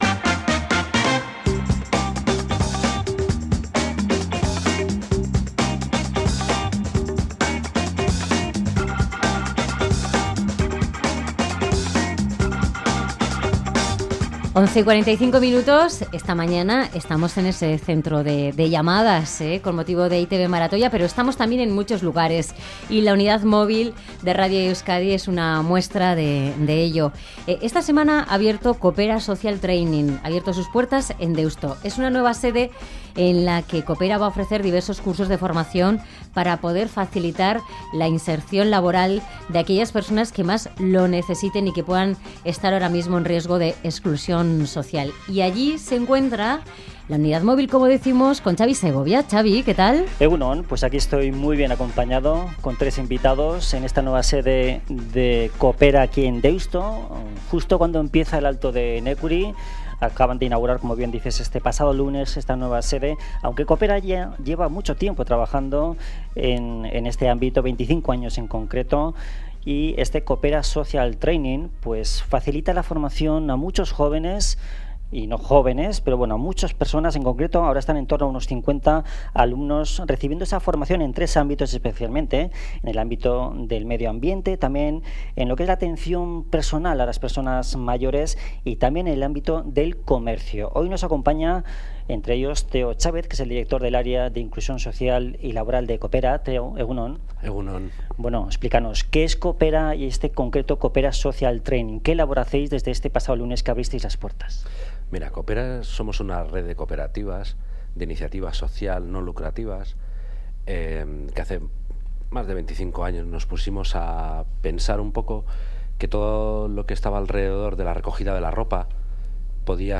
La unidad móvil se ha acercado hasta aquí para hablar con sus responsables y alumnos.